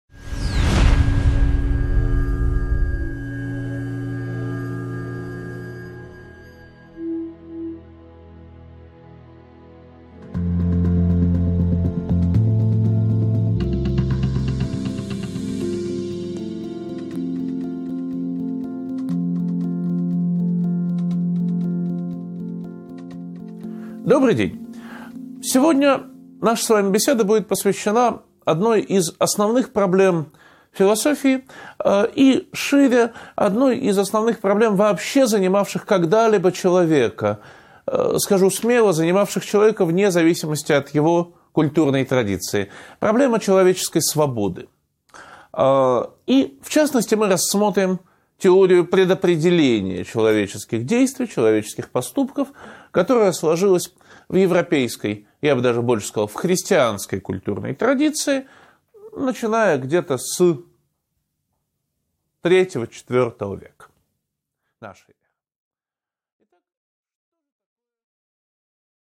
Аудиокнига 8.1 Свобода человека: введение | Библиотека аудиокниг